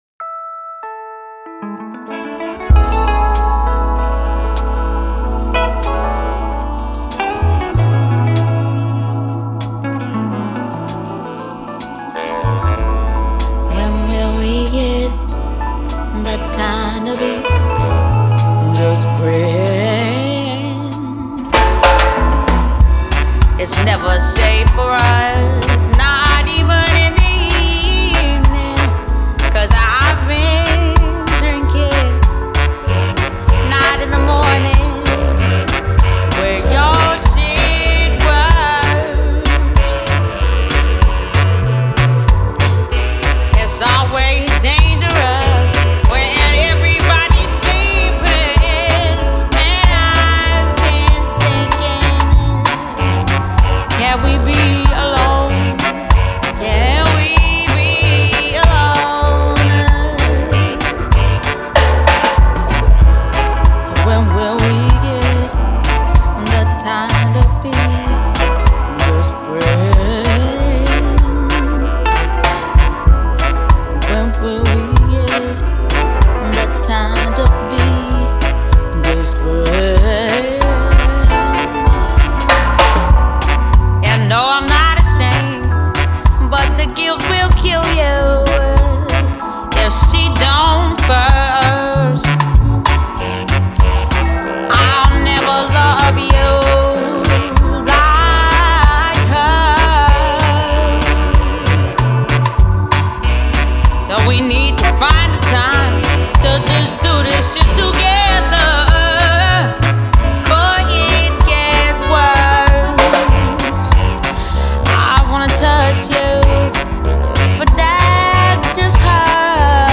Live Scotland 2008